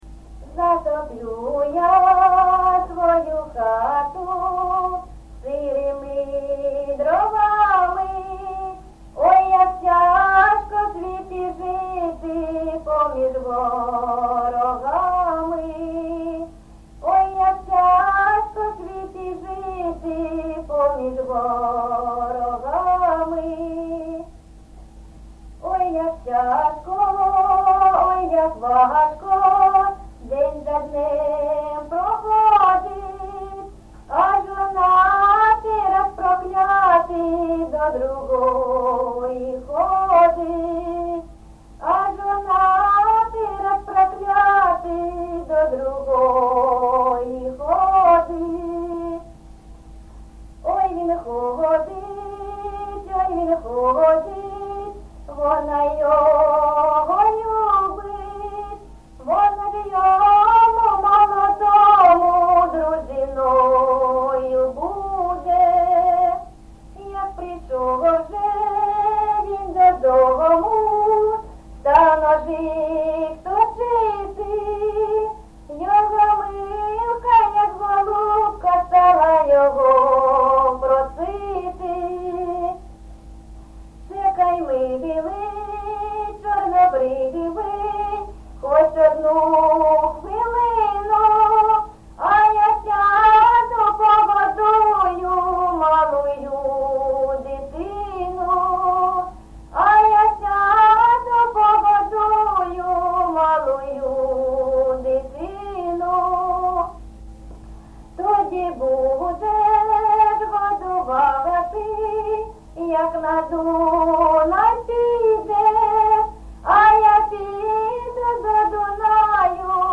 ЖанрПісні з особистого та родинного життя
Місце записус-ще Троїцьке, Сватівський район, Луганська обл., Україна, Слобожанщина